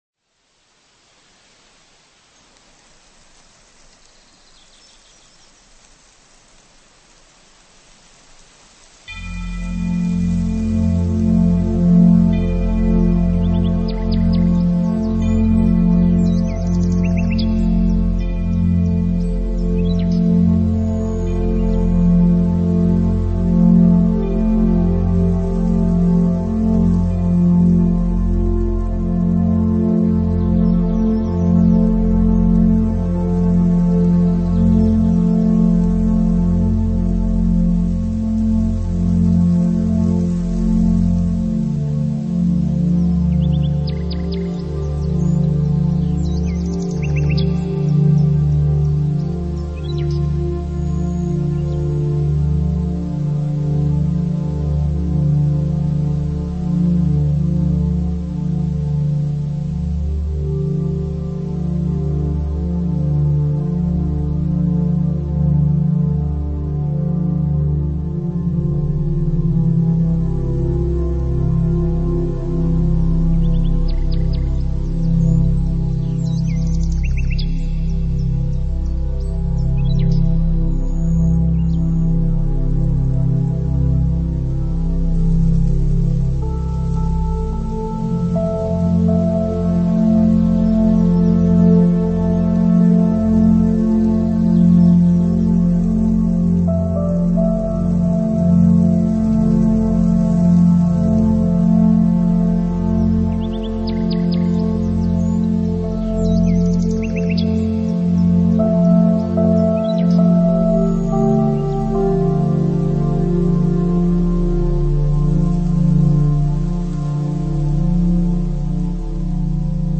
nature sounds and atmospheric sounds, perfect for relaxation
ambient
new age